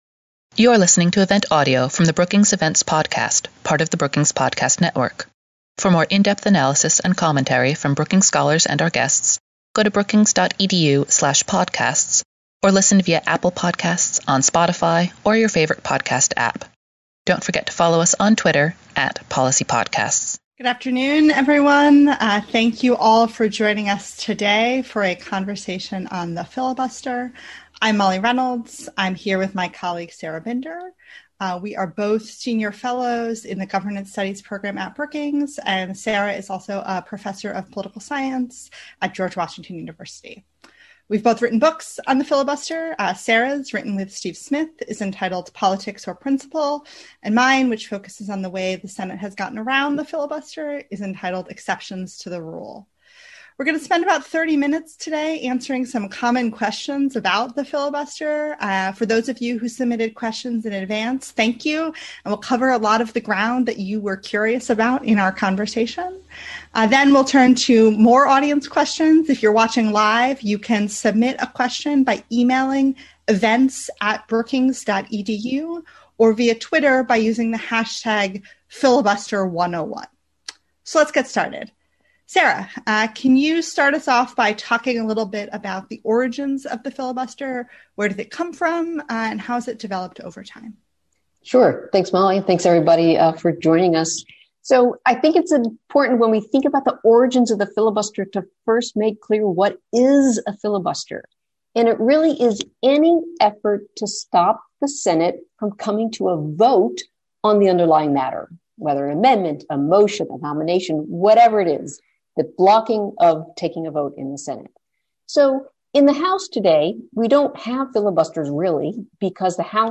On April 6, Governance Studies at Brookings will host a primer on everything you want to know about the filibuster but are afraid to ask.